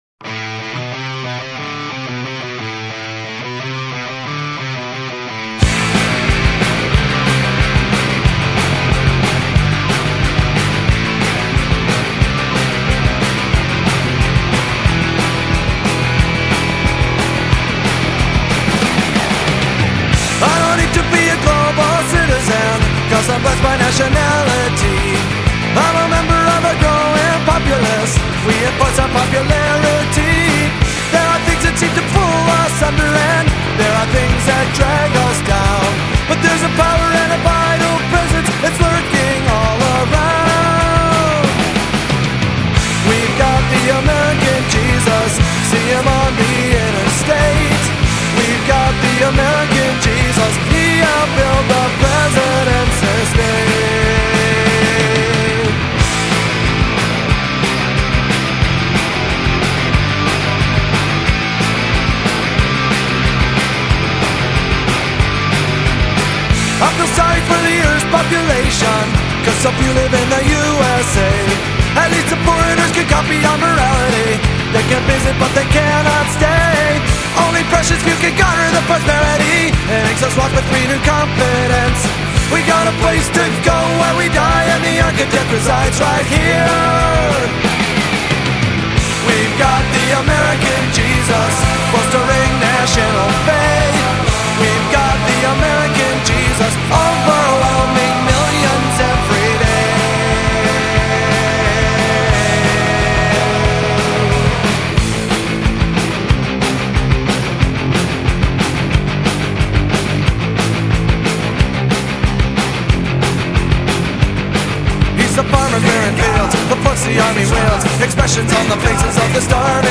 Radio remix version (3:16)